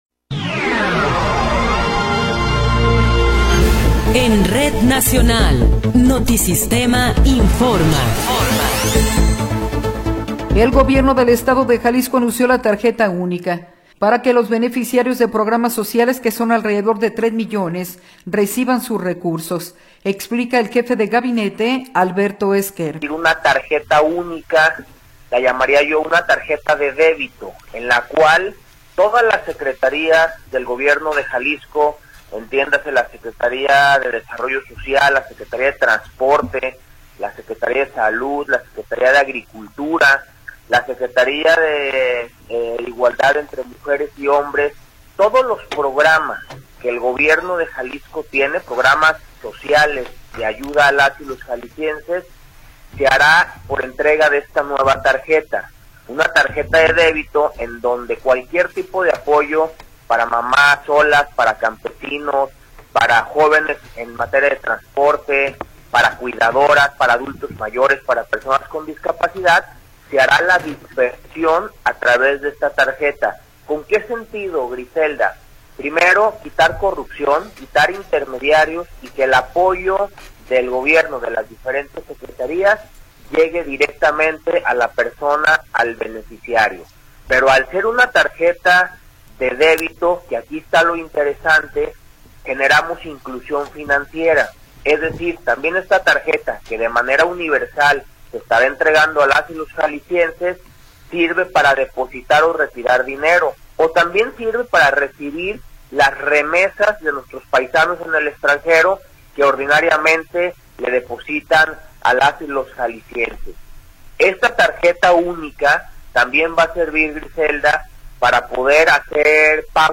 Noticiero 17 hrs. – 1 de Enero de 2026
Resumen informativo Notisistema, la mejor y más completa información cada hora en la hora.